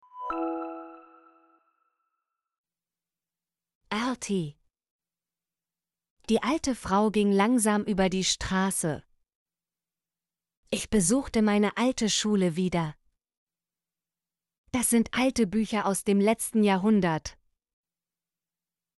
alte - Example Sentences & Pronunciation, German Frequency List